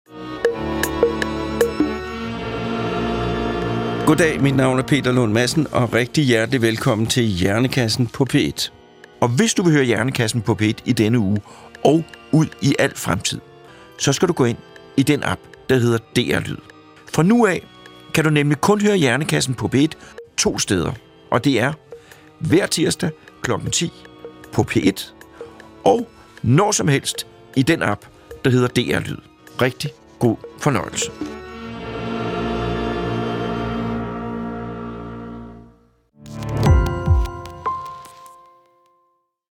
Peter Lund Madsen får hver uge besøg af eksperter i studiet. Alle emner kan blive belyst - lige fra menneskekroppens tarmflora til ekspeditioner på fremmede planeter.